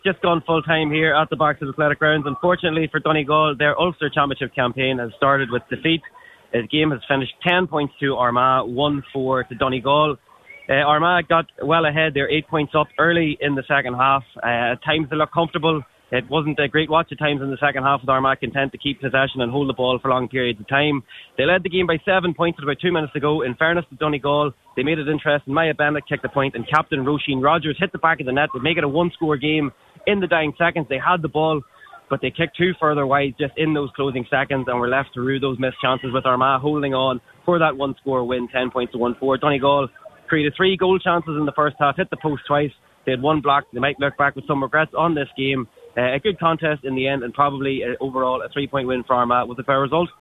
reports from the Athletic Grounds for Highland Radio Sport